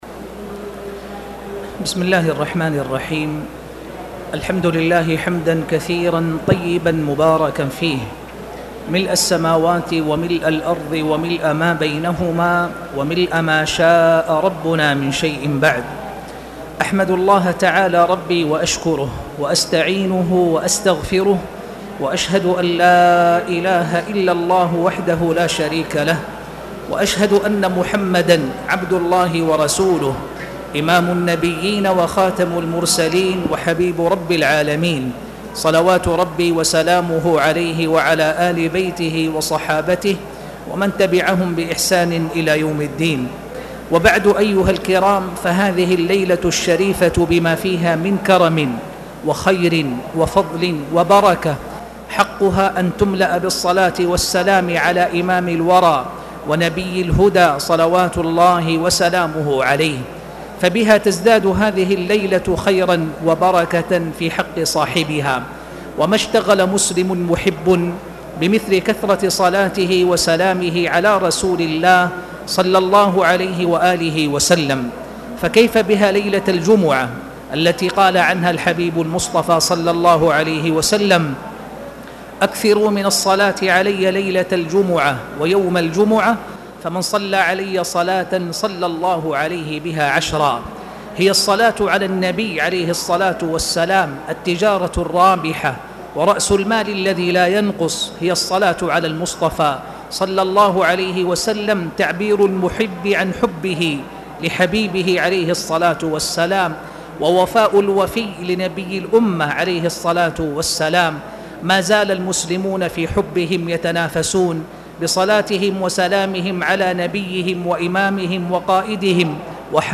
تاريخ النشر ١٦ رجب ١٤٣٨ هـ المكان: المسجد الحرام الشيخ